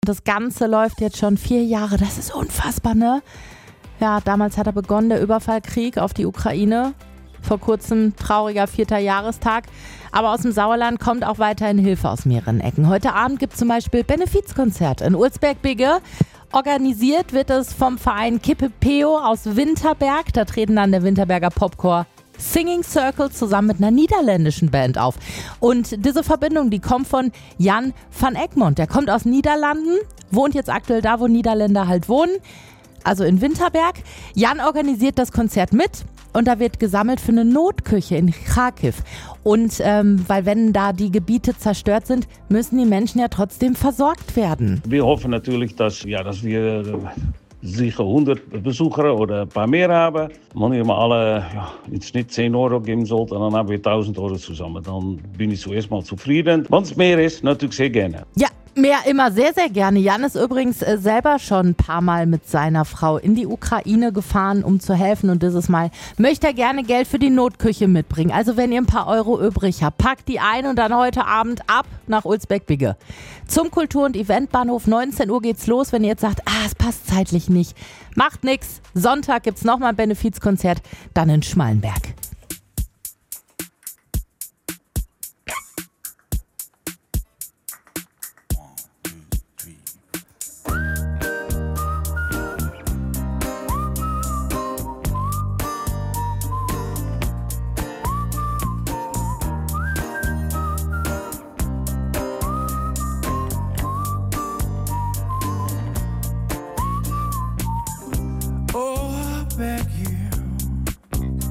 im Gespräch mit Radio Sauerland